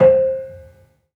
Kenong-dampend-C4-f.wav